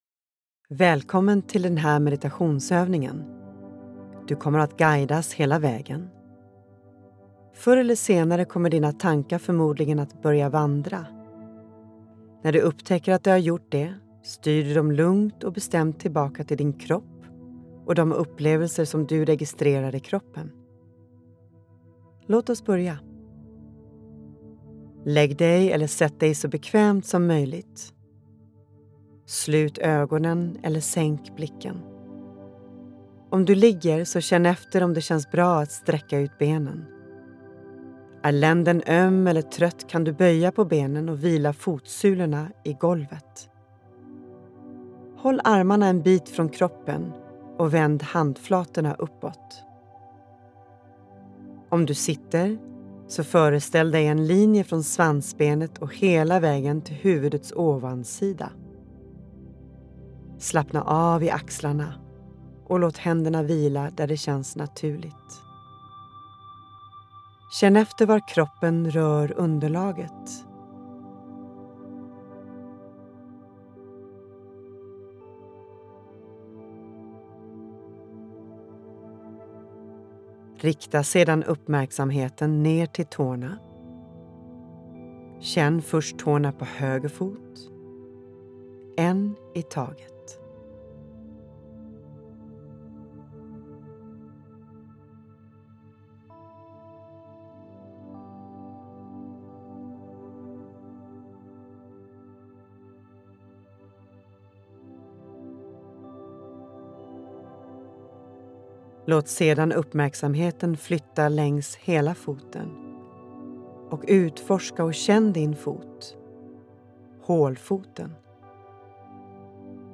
Lyssna på en guidad meditation här.
5_kroppsscanning.mp3